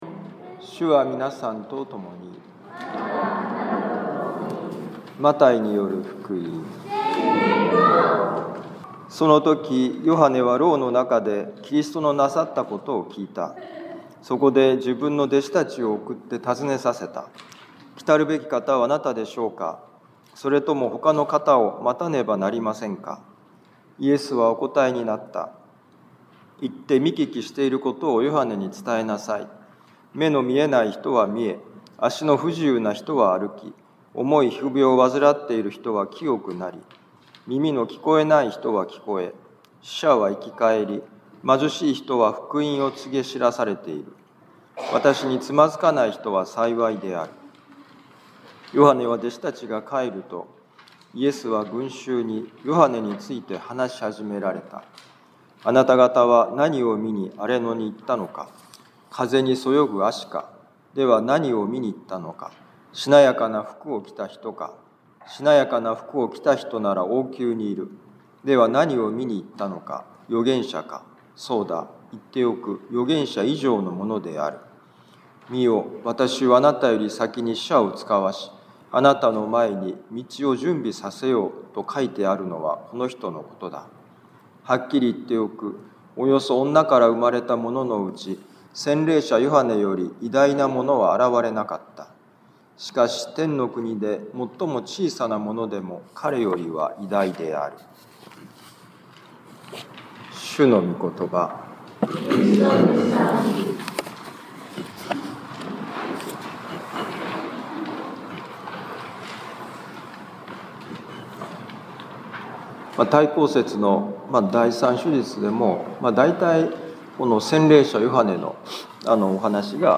マタイ福音書11章2-11節「神の恵みを思い起こそう」2025年12月14日待降節第3主日ミサ カトリック防符教会